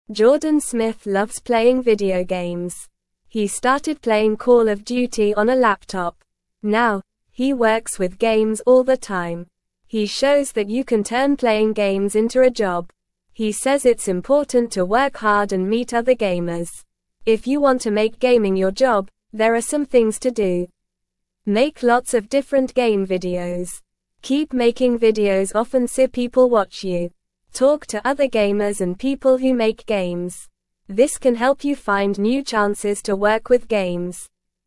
Normal
English-Newsroom-Beginner-NORMAL-Reading-Playing-Games-Can-Be-a-Job.mp3